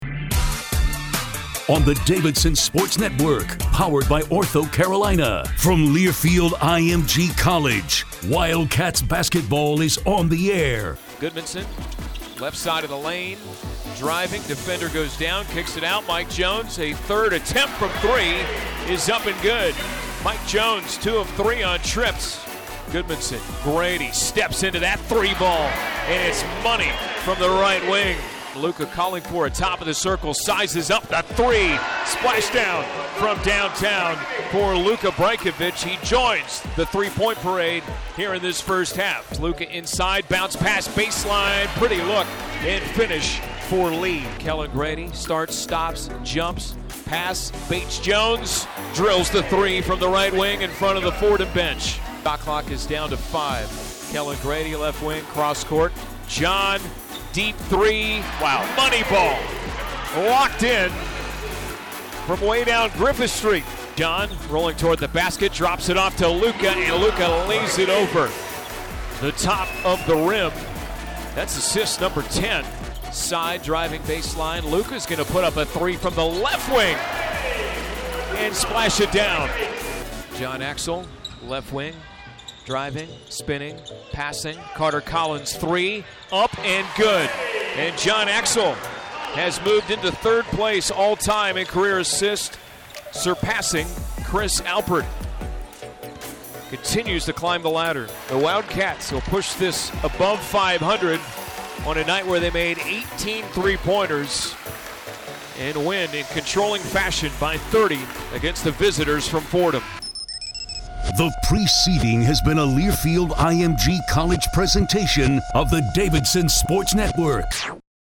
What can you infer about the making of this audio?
Radio Highlights